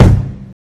KICK BAM.wav